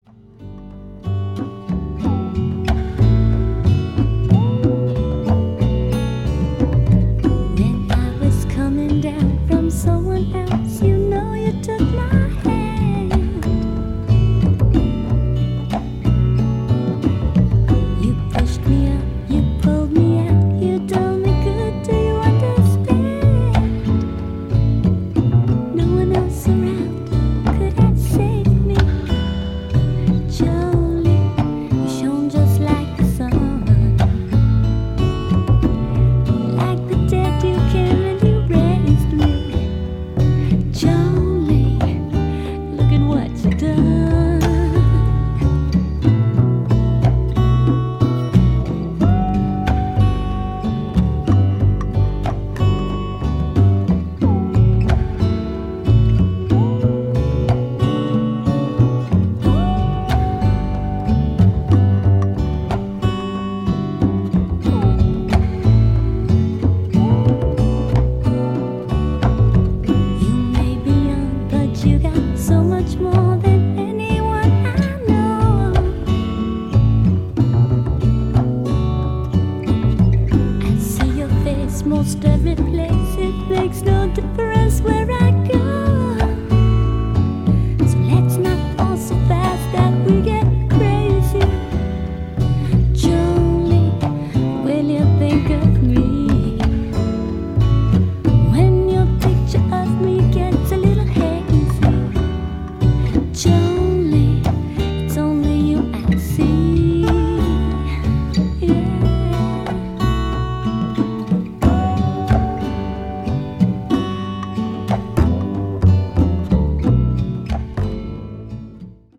ジャンル(スタイル) JAPANESE POP / FREE SOUL / HOUSE